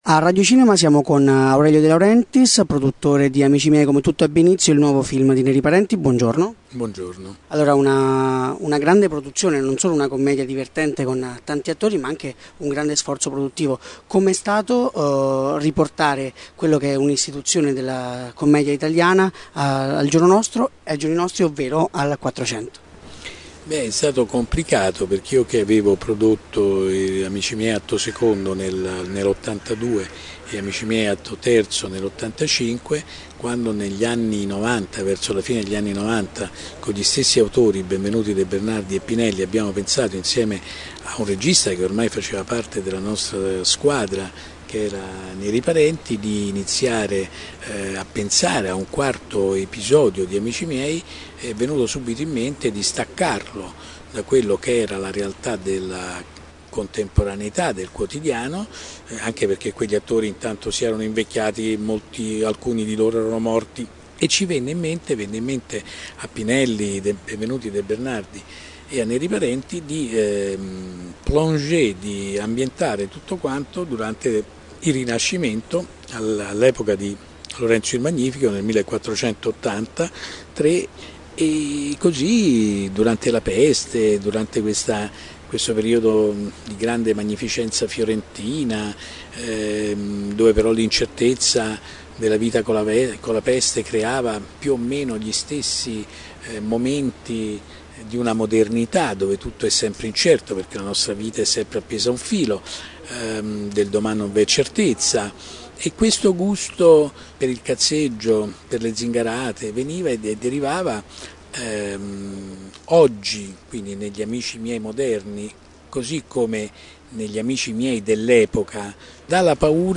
Aurelio De Laurentiis, il produttore
podcast_Intervista_Aurelio_de_Laurentiis_Amici_Miei.mp3